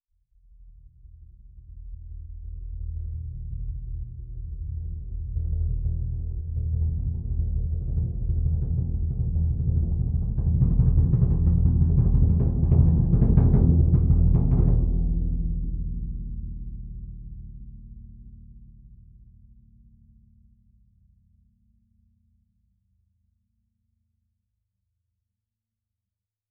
bassdrum_cresc_med.wav